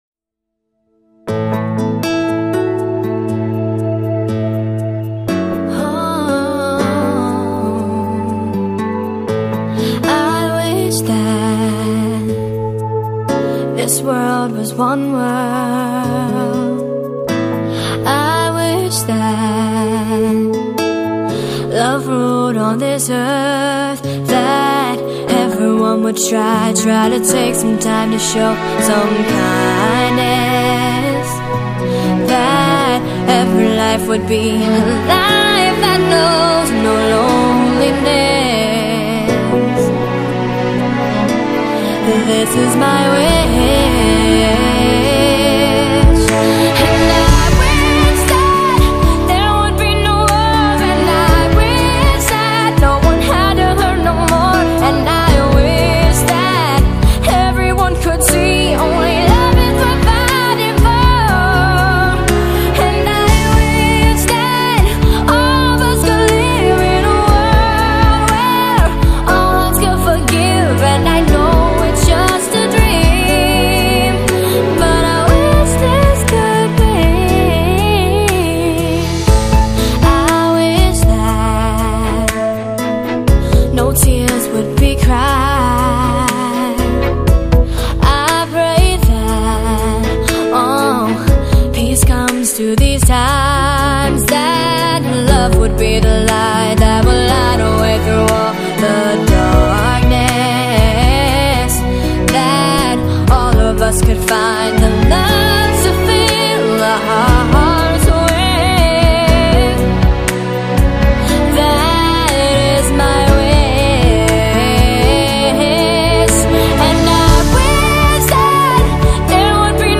全场观众起立喝彩，惊的现场评委都捂住嘴巴站了起来。